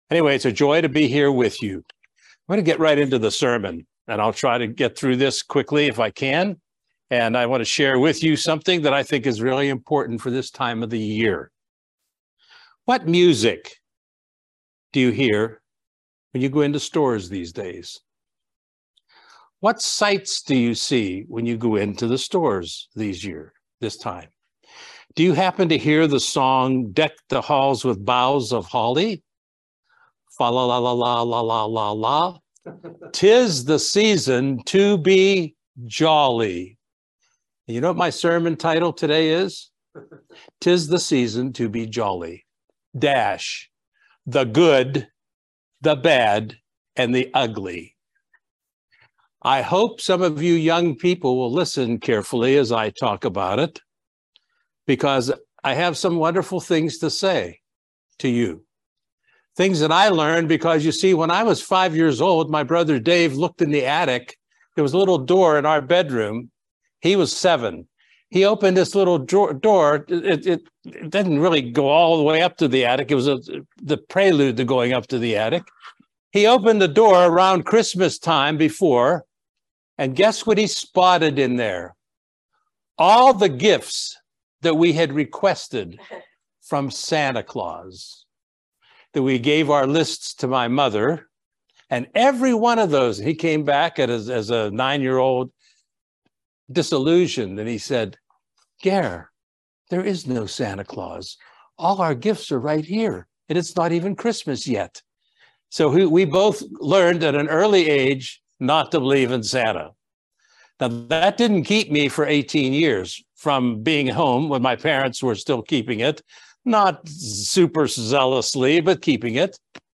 This sermon reveals a clear understanding about one of this world's favorite holidays.